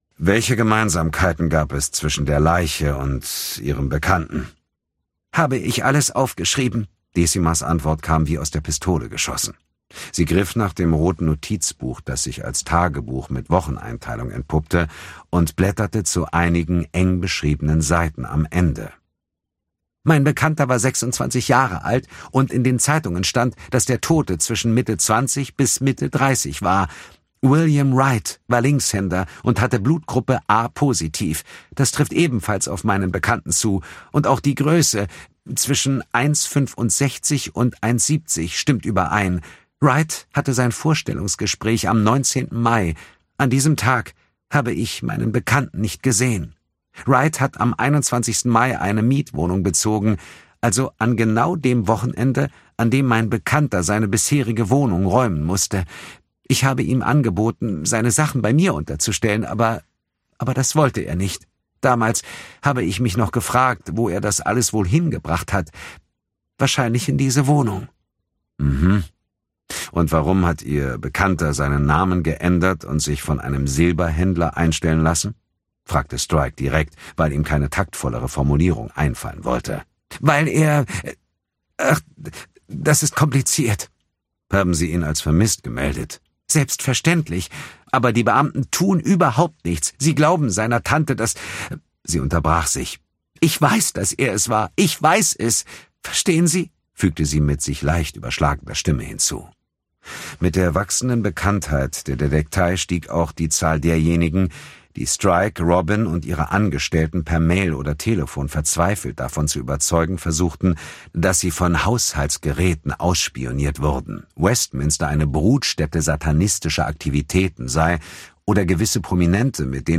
Dietmar Wunder (Sprecher)
ungekürzte Lesung